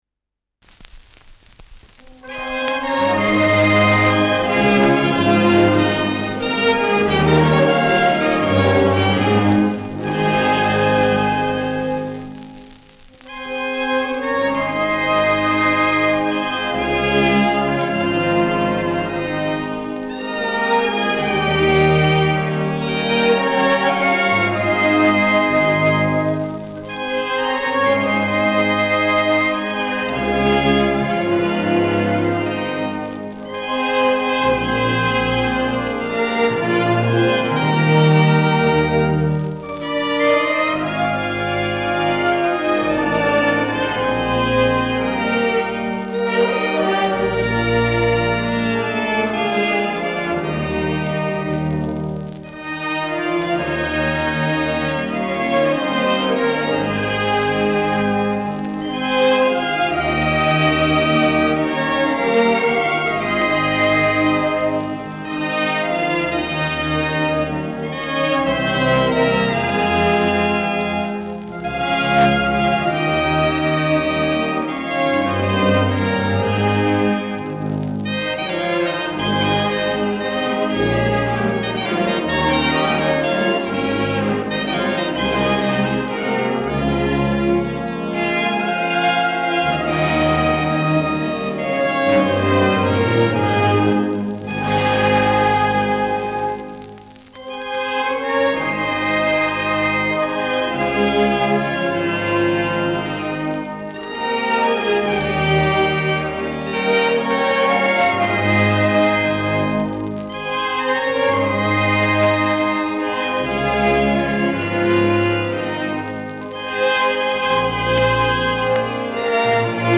Schellackplatte
präsentes Knistern : leichtes Rauschen : leiert
Große Besetzung mit viel Hall, die einen „symphonischen Klang“ erzeugt.
[Berlin] (Aufnahmeort)